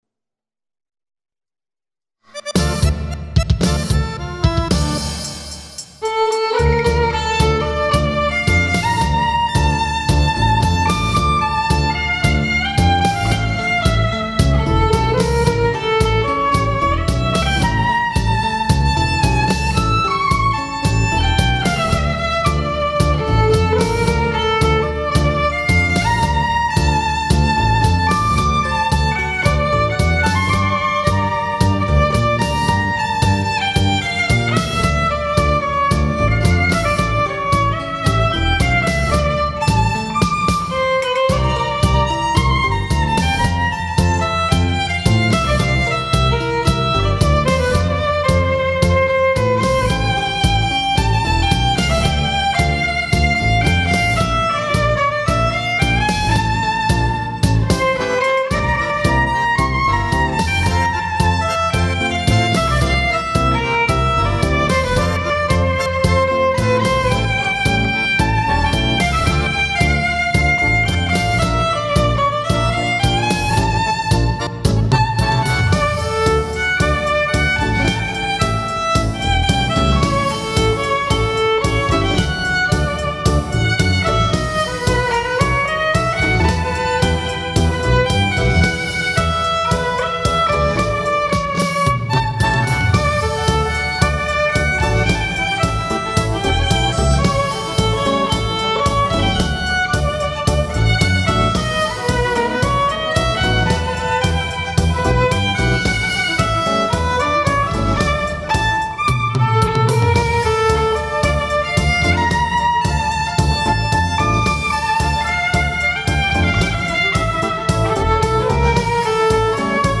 с замечательной скрипкой и замечательным танго